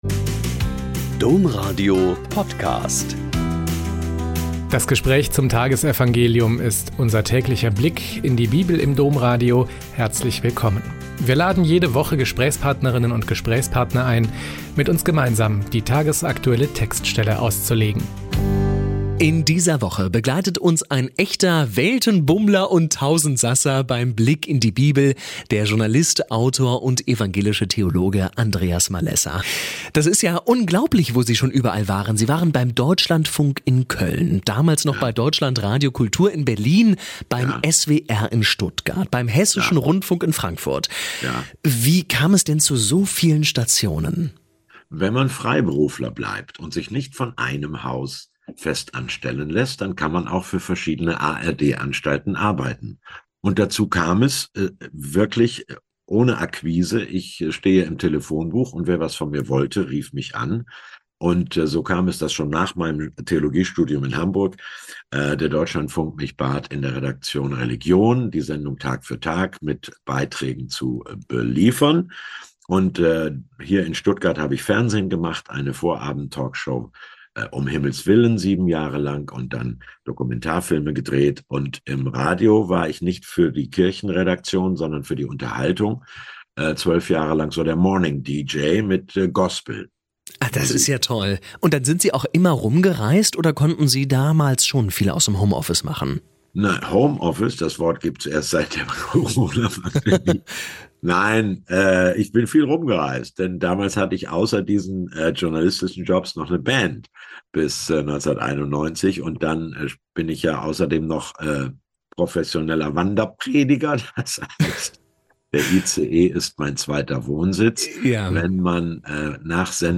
Lk 15,1-10 - Gespräch